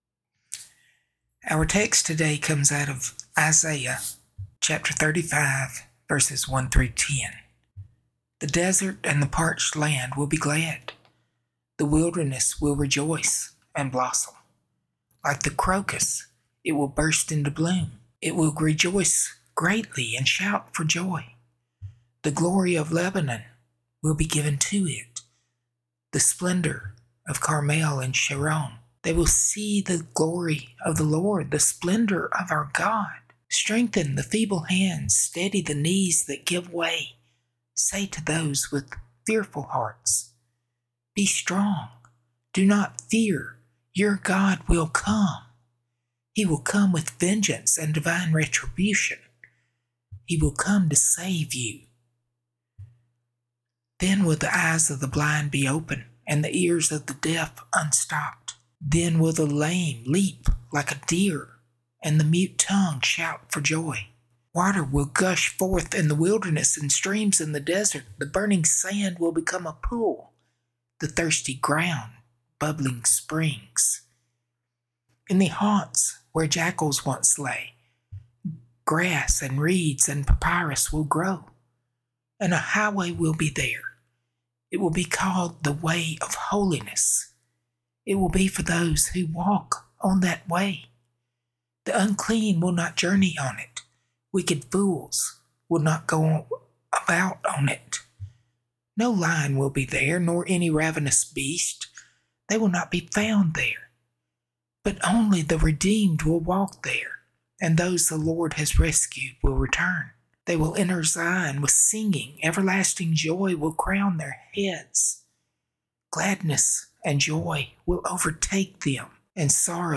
00:10 Direct Link to sermon Sermon Handout Recent Sermons Held in the Wilderness Seeing His Glory Salt and Light What the Lord Requires Calling Us to Follow